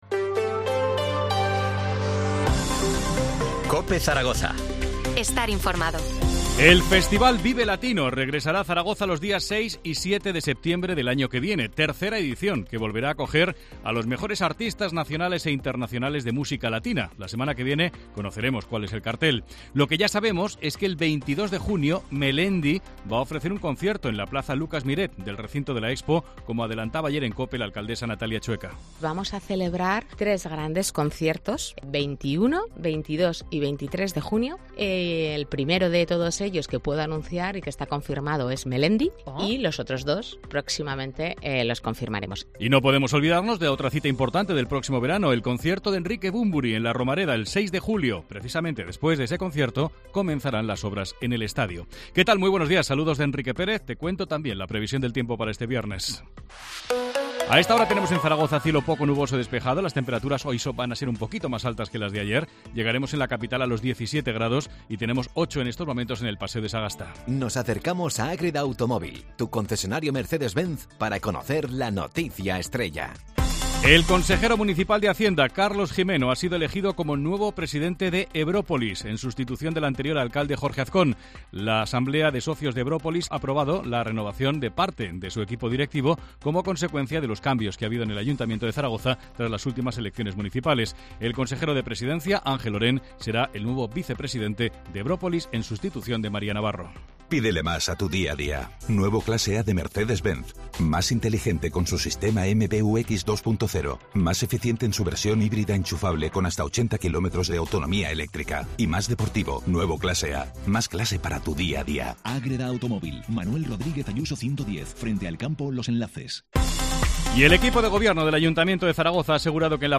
Titulares del día en COPE Zaragoza